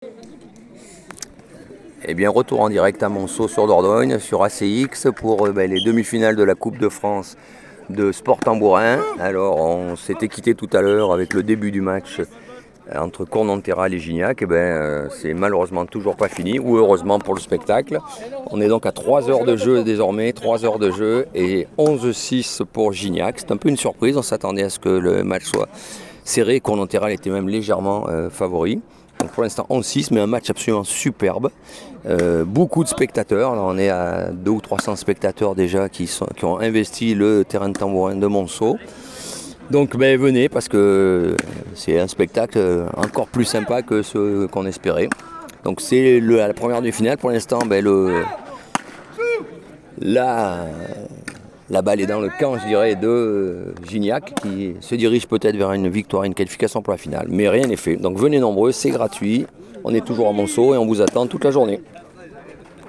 tambourins11.mp3